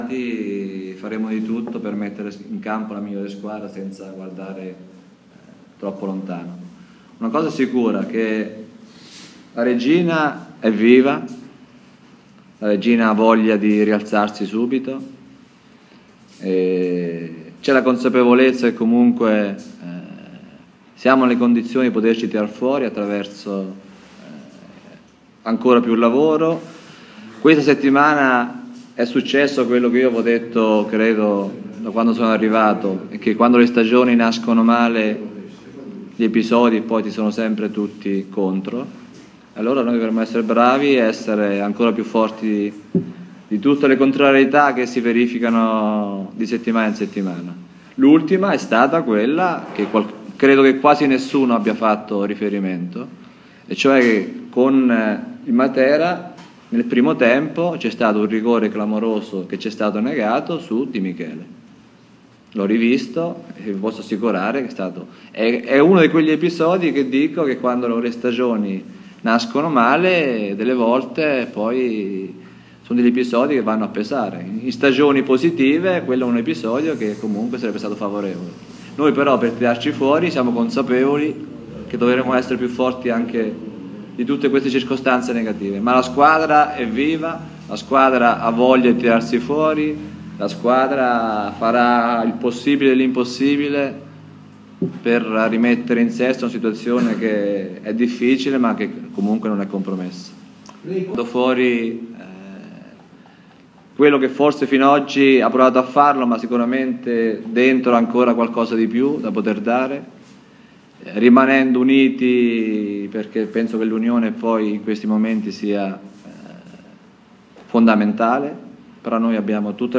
Lunga e significativa conferenza stampa